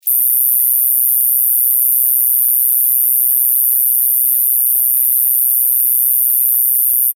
自然・動物 （58件）
クビキリギス.mp3